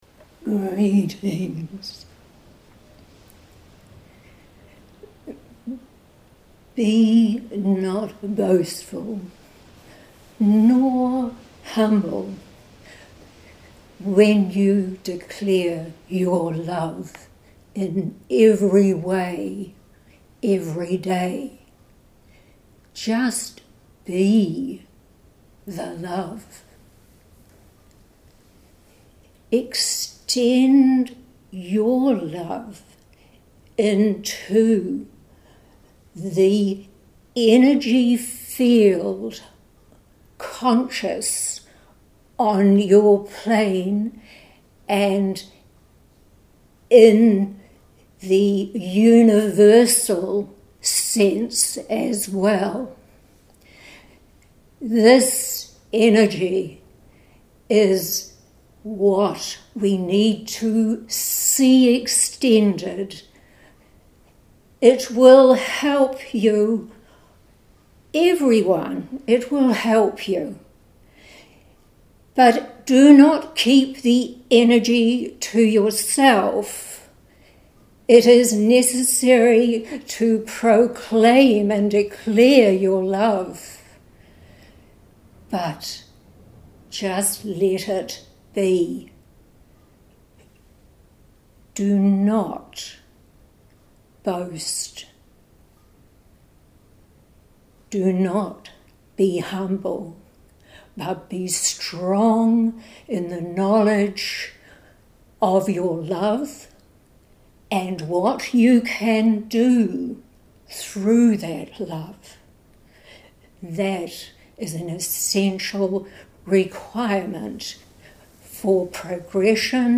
at our weekly meditation group.